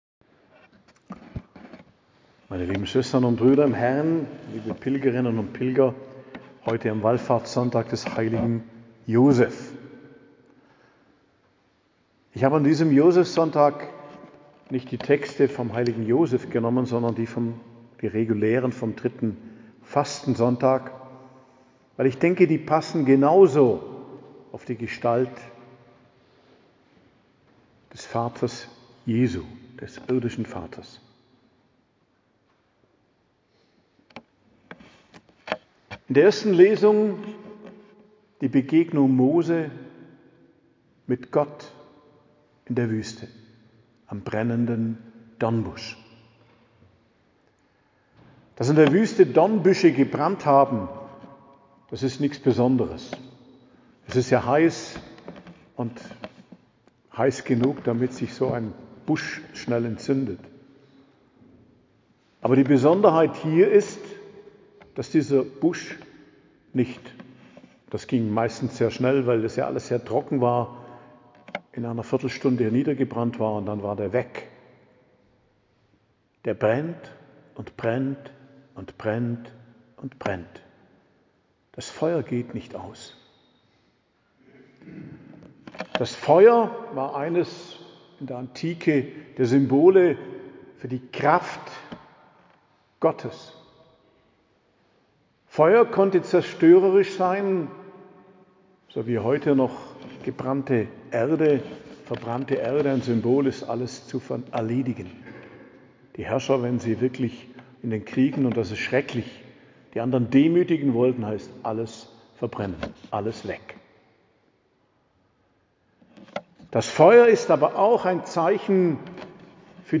Predigt zum 3. Fastensonntag am 23.03.2025 ~ Geistliches Zentrum Kloster Heiligkreuztal Podcast